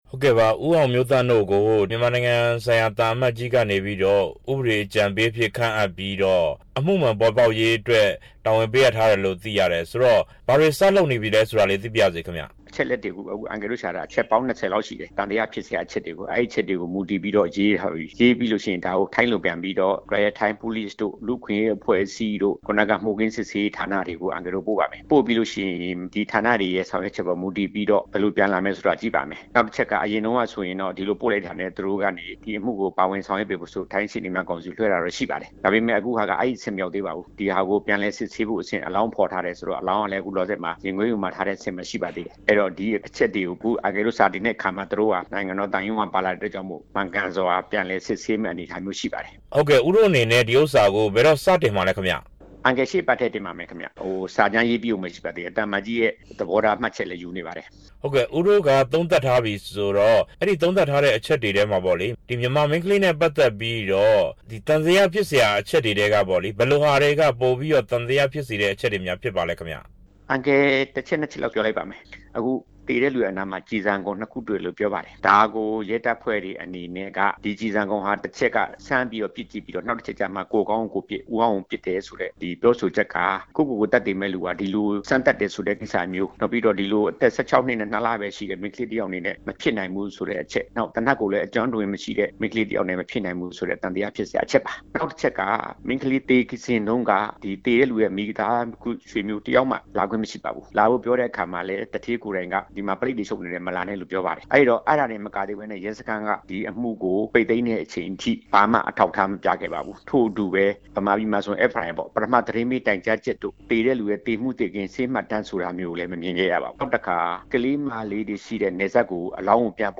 ထိုင်းနိုင်ငံက မြန်မာအိမ်ဖော်သေဆုံးမှု အမှုမှန်ပေါ်ပေါက်ရေး ရှေ့နေနဲ့ မေးမြန်းချက်